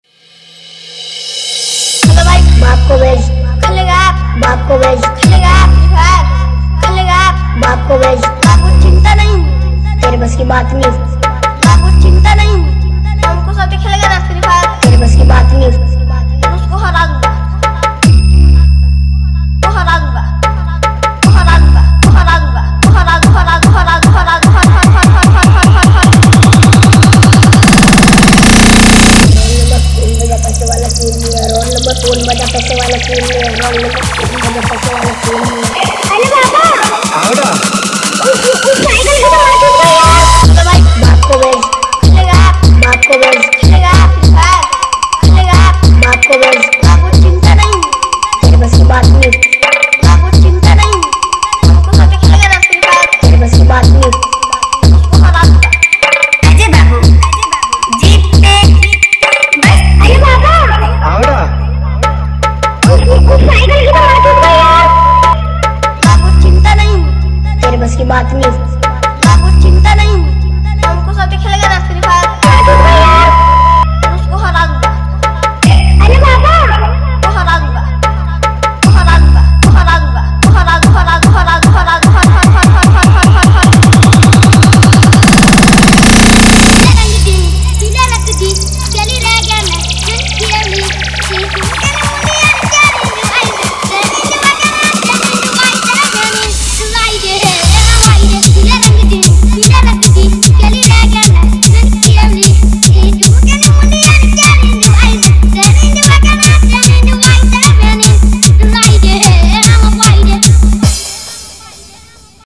Category : Comptition Wala Dj Remix